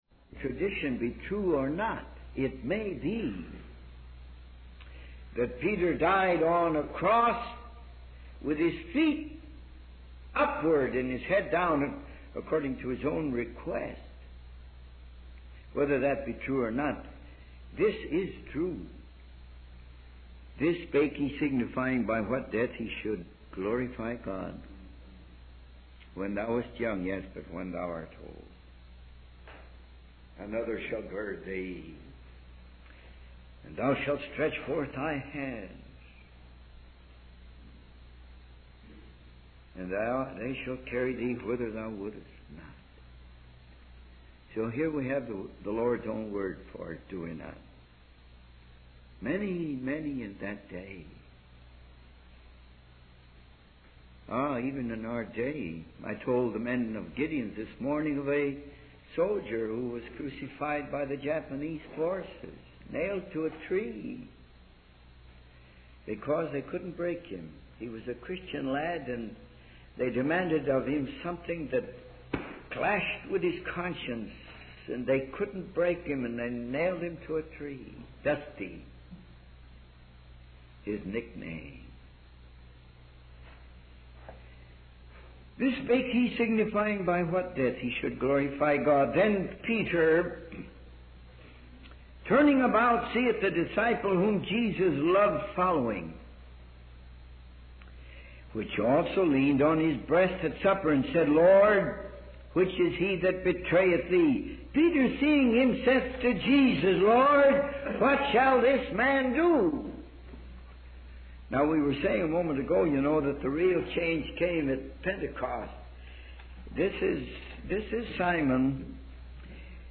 In this sermon, the speaker shares a story about a young man who witnessed a humble washerwoman's clothes shining brightly in the sunlight.